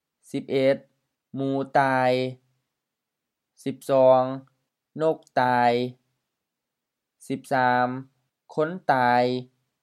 หมู mu: M
ตาย ta:i M
นก nok H
คน khon HR